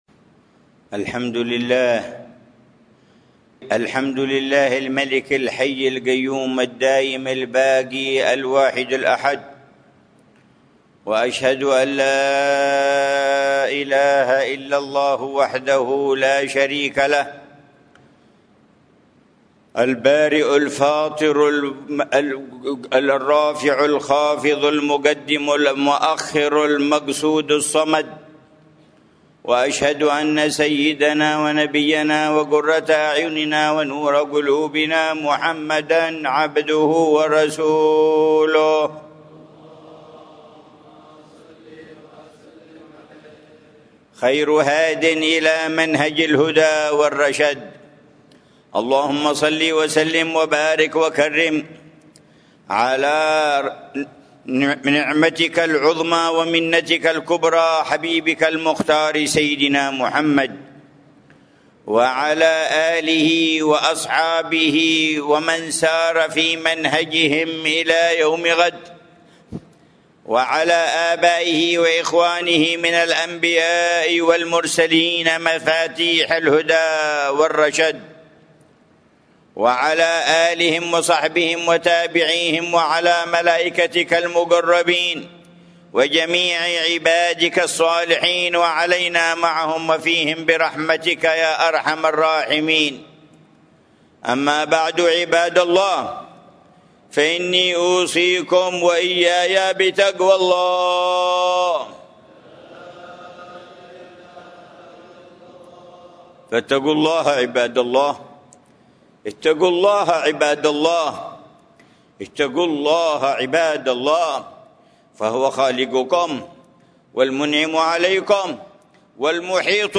خطبة الجمعة
في جامع الإيمان في عيديد، مدينة تريم